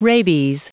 Prononciation du mot rabies en anglais (fichier audio)
Prononciation du mot : rabies